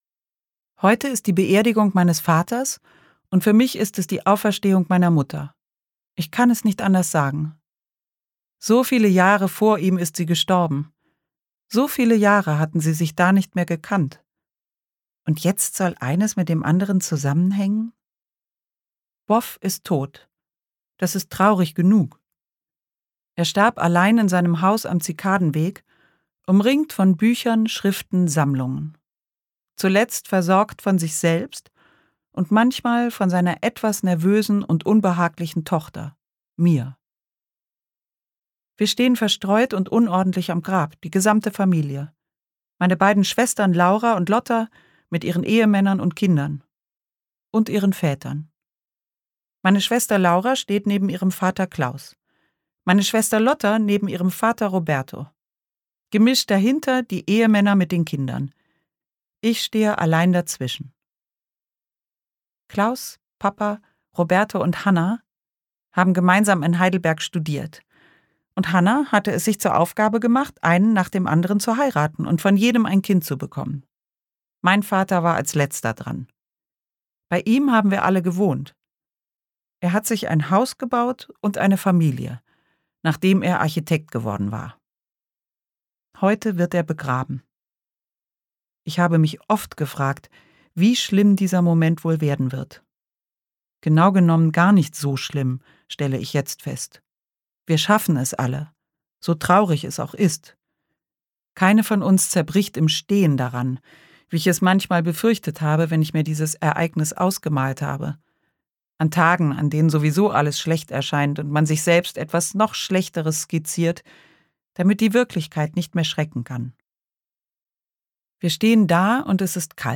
Ein anderes Leben Caroline Peters (Autor) Caroline Peters (Sprecher) Audio Disc 2024 | 2.